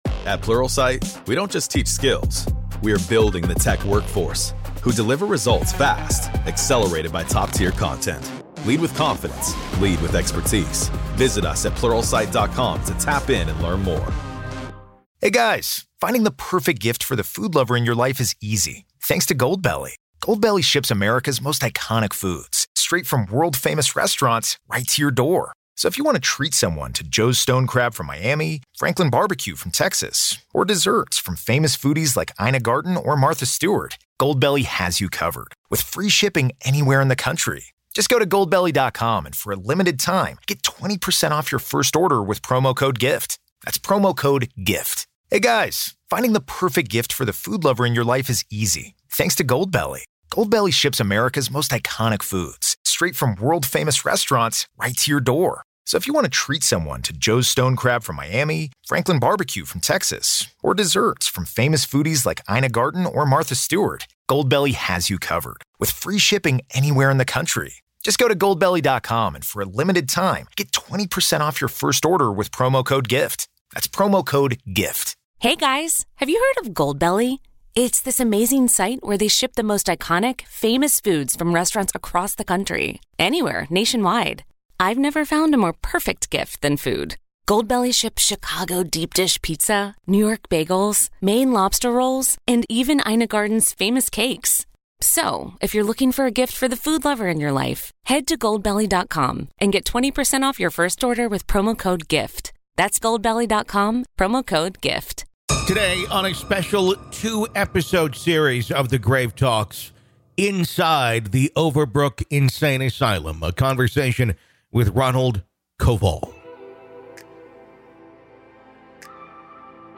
During his time there, he would experience countless haunting and paranormal experiences that can not be explained. From time warps to conversations with patients who have passed, and much more. This is Part One of our conversation.